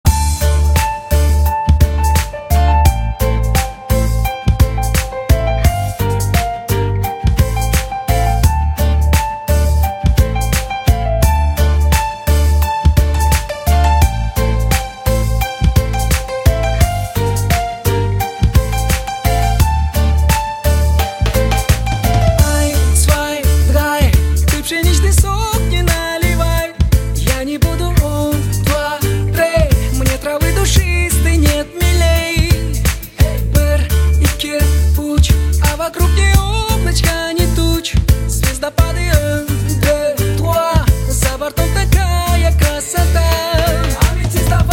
• Качество: 128, Stereo
легкость